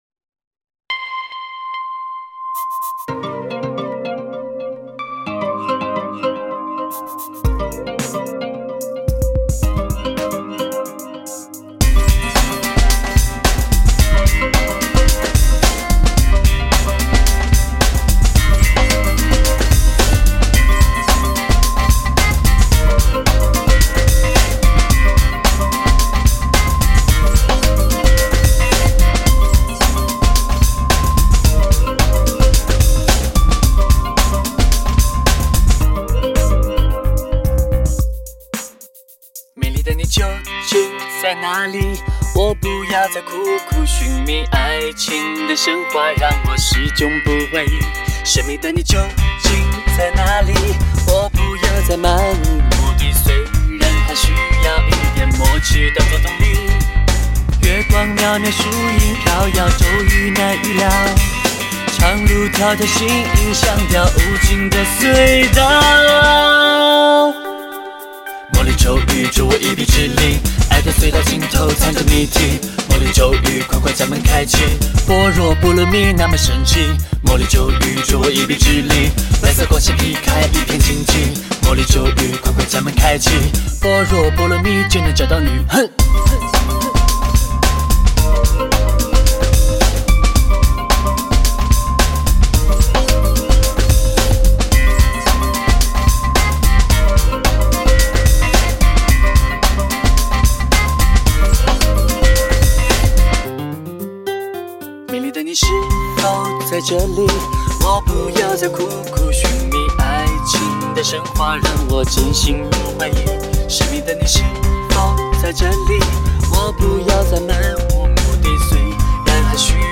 专辑中的歌曲曲风各异，R＆B和电子曲风的运用，让每首歌曲都个性突出。